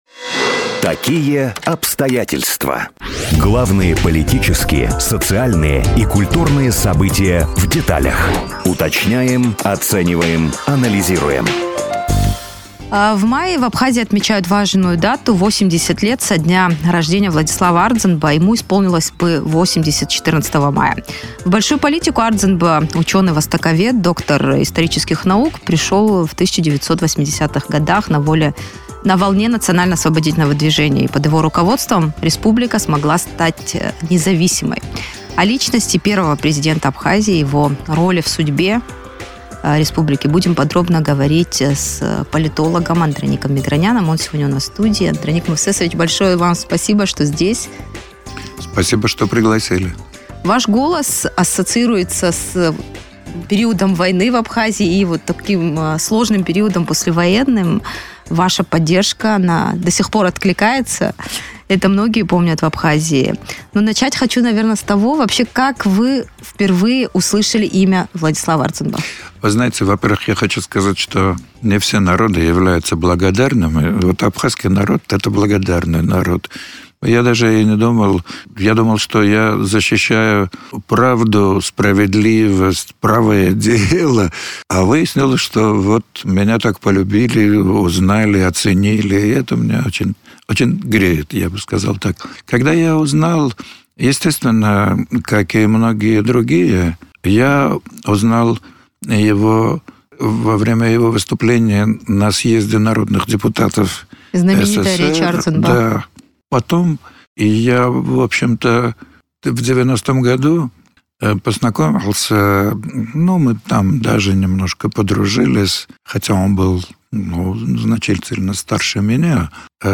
О личности первого президента Абхазии, его роли в судьбе республики в интервью радио Sputnik рассказал...